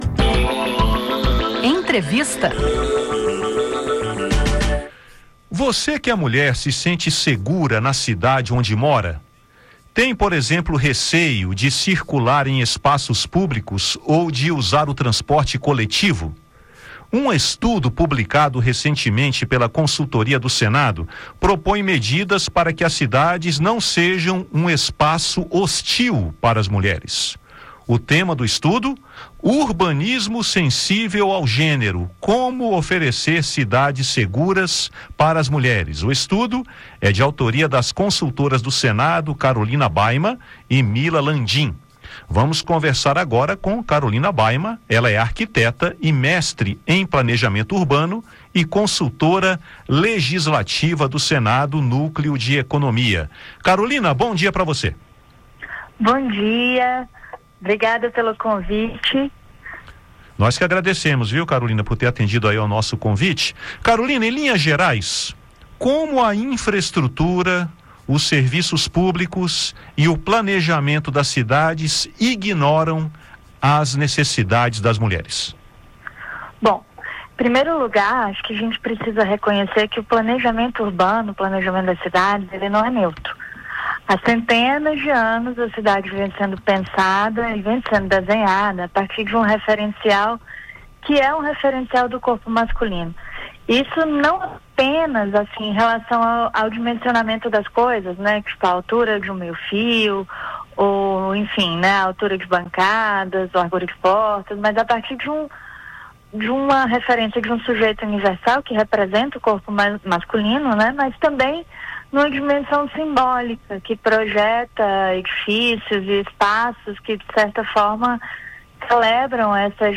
Em entrevista ao Conexão Senado